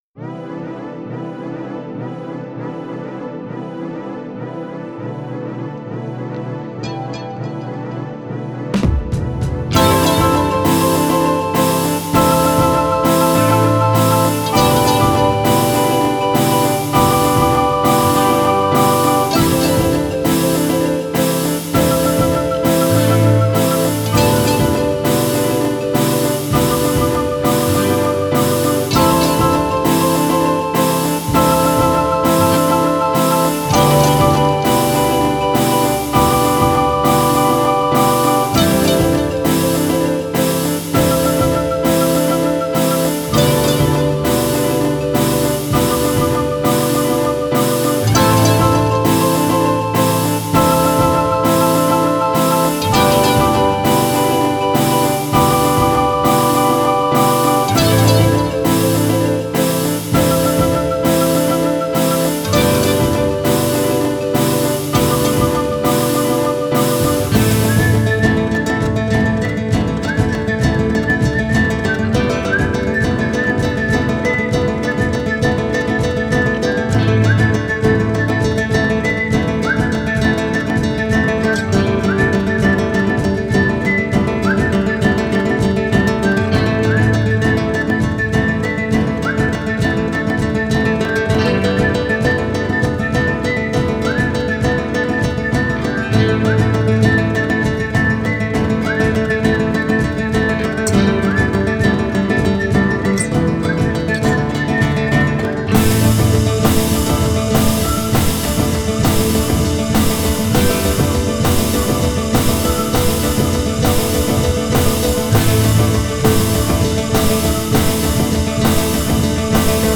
ギター、うた、笛、ピアノ、打楽器